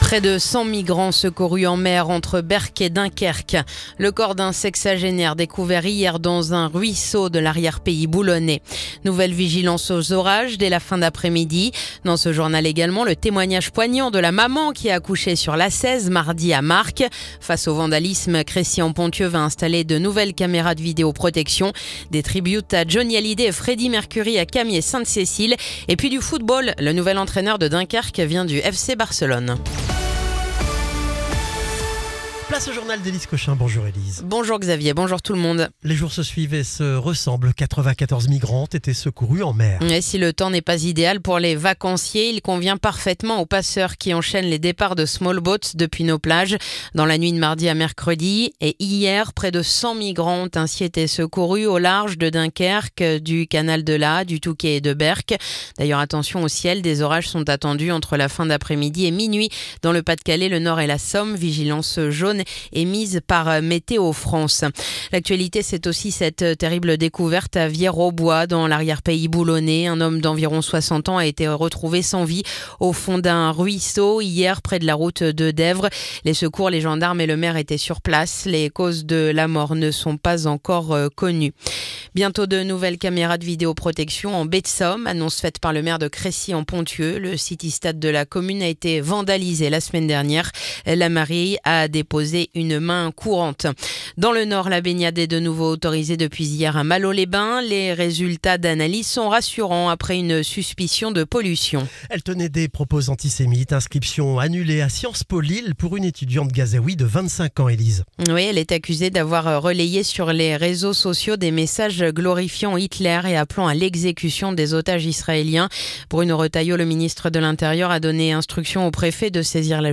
Le journal du jeudi 31 juillet